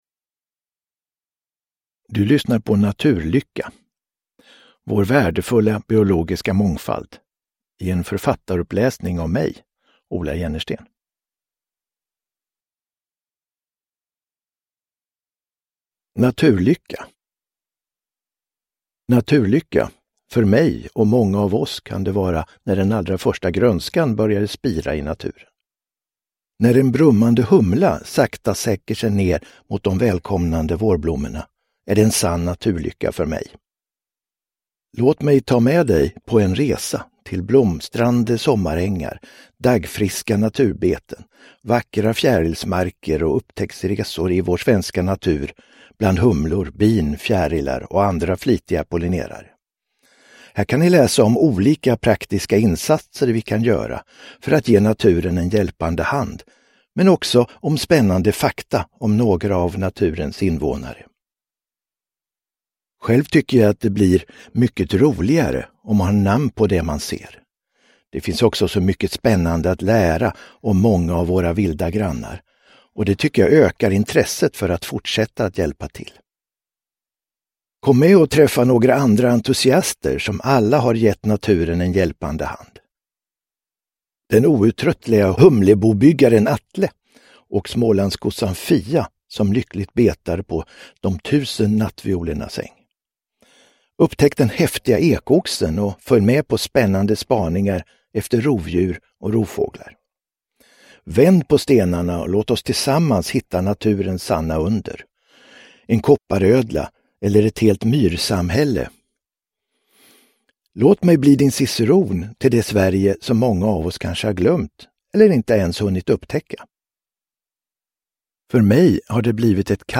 Naturlycka : vår värdefulla biologiska mångfald – Ljudbok – Laddas ner